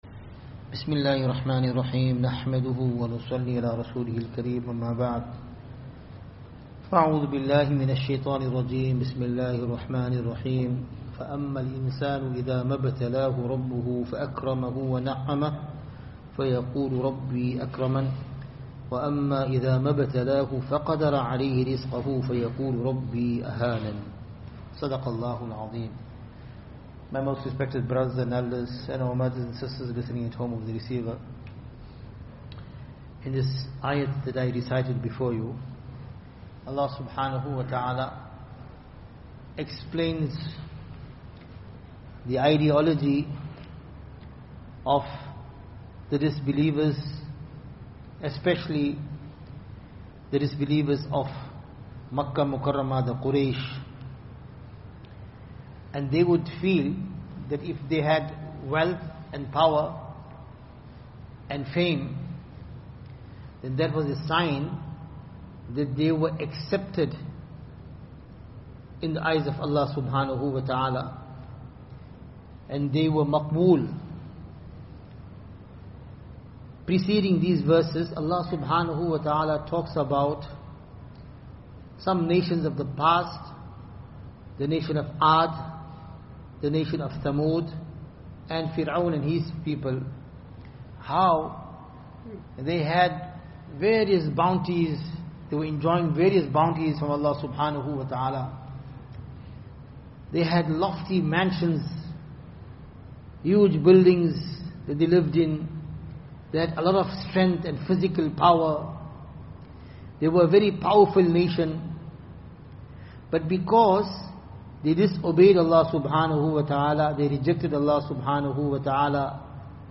22nd January 2026 – Zikr Majlis